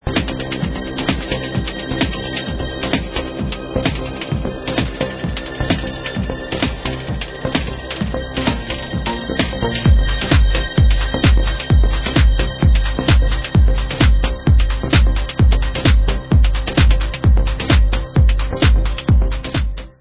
sledovat novinky v oddělení Dance/Techno